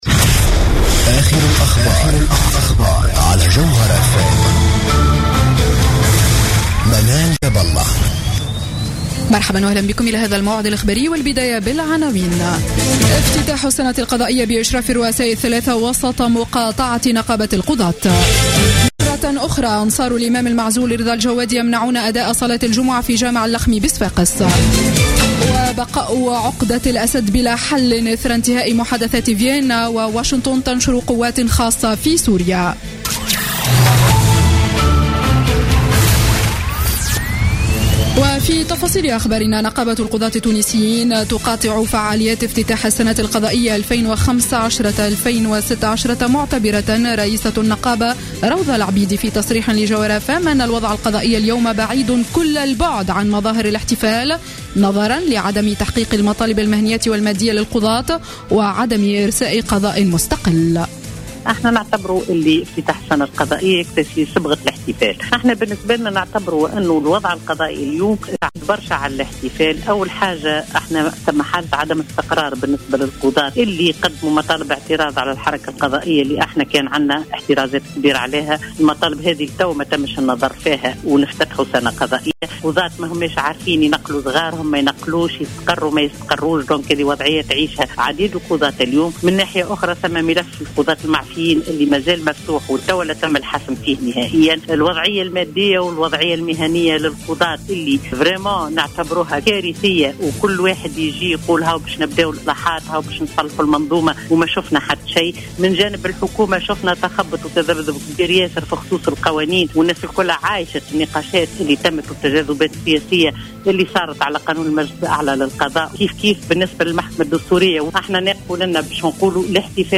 نشرة أخبار السابعة مساء ليوم الجمعة 30 أكتوبر 2015